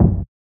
[ACD] - Ludacris Kick.wav